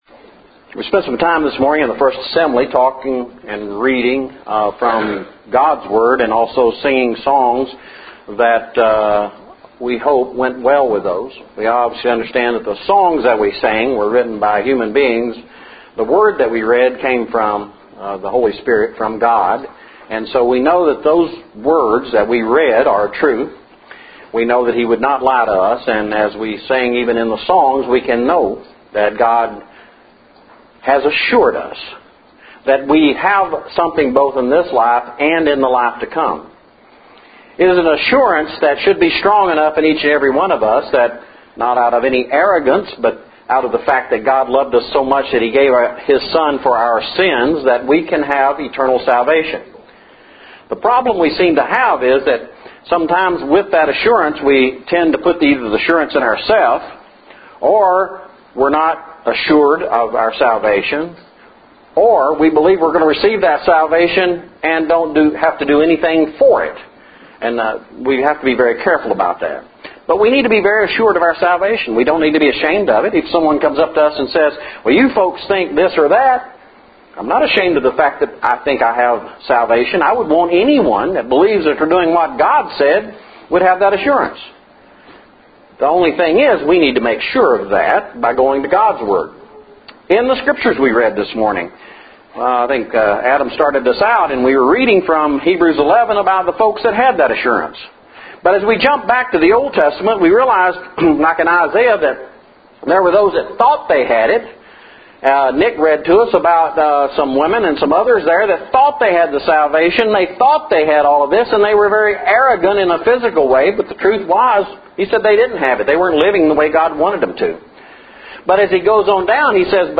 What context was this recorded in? Our 5th Sunday of the Month lesson – where we do songs and scripture readings for our first assembly – began our worship service focused on “Blessed Assurance”.